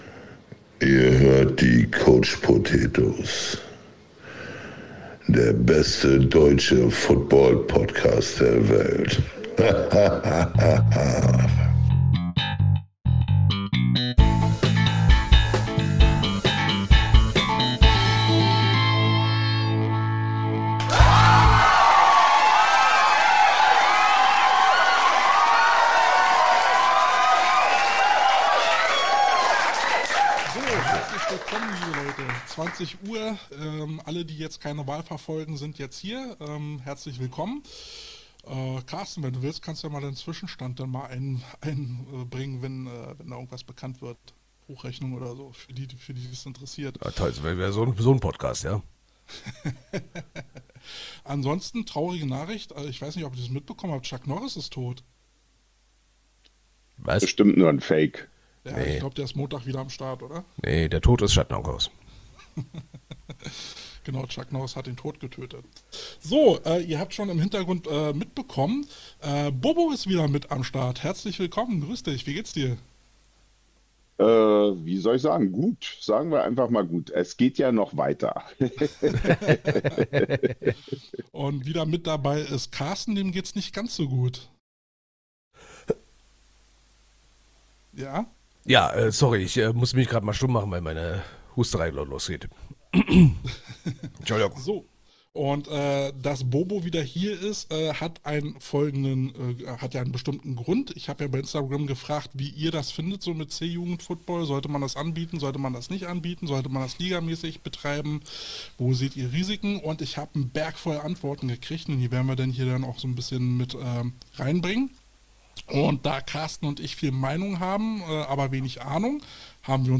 Die Diskussion im Podcast war hitzig, ehrlich und genau so, wie Football sein muss.